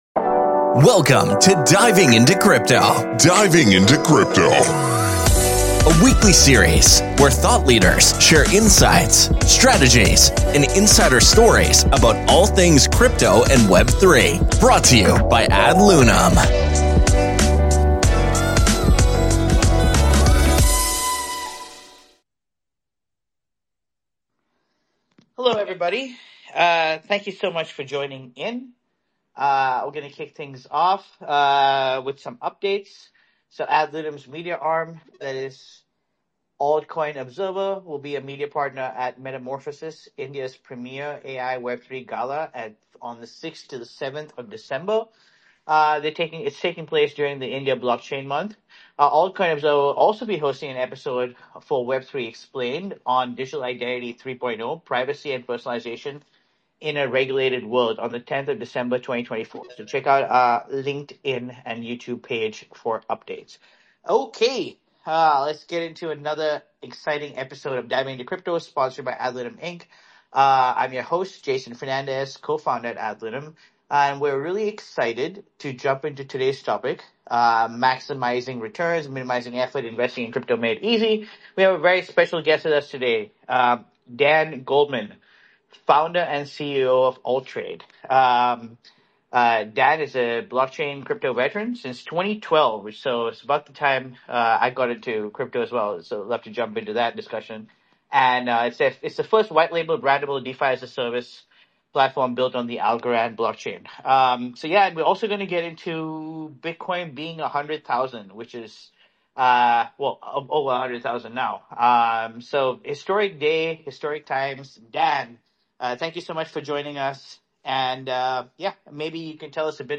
Tune in for an insightful discussion packed with expert knowledge on decentralized finance, Web3 innovation, and the challenges and opportunities ahead in the blockchain space.